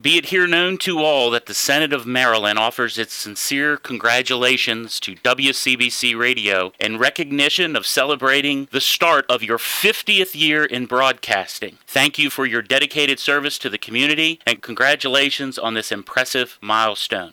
During a special visit to the studios of WCBC on Tuesday, Maryland State Senator Mike McKay brought along an early gift, kicking off WCBC’s 50th anniversary year.